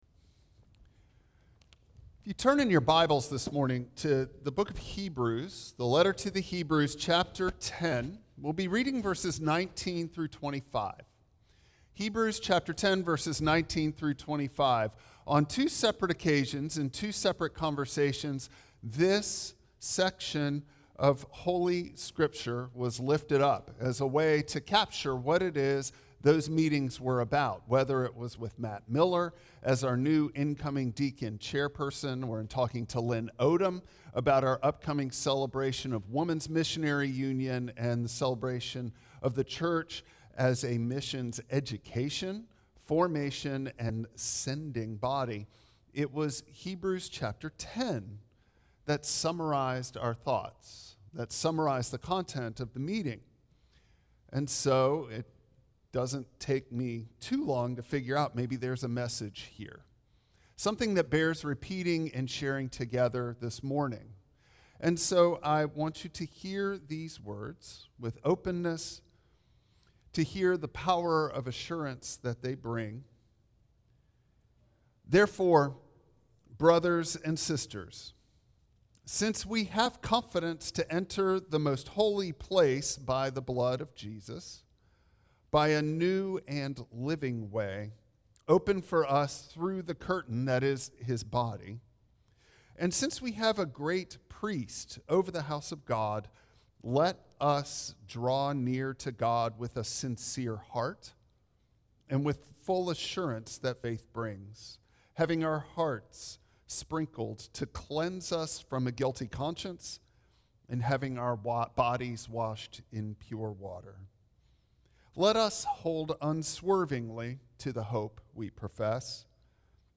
Note: this sermon audio also includes testimony from 2 newly ordained deacons and the congregational dedication of newly appointed deacons.
Service Type: Traditional Service